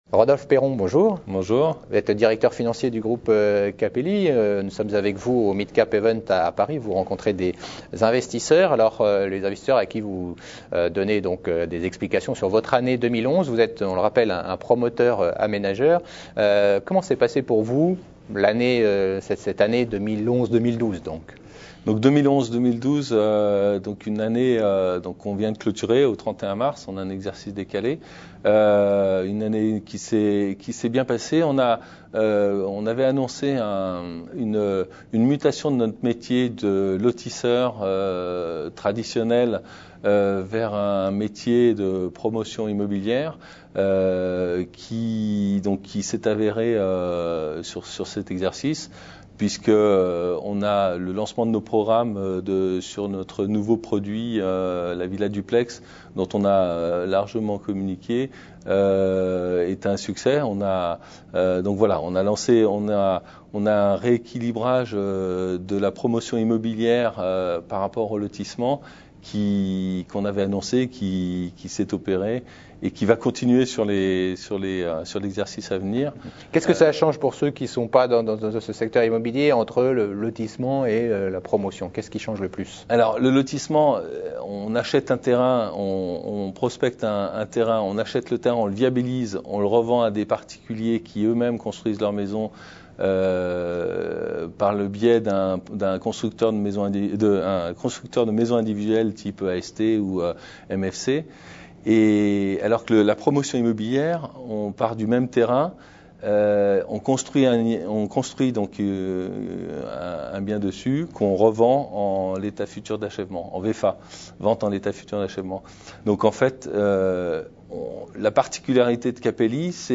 Smallcap event à Paris.